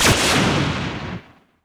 laserstop.wav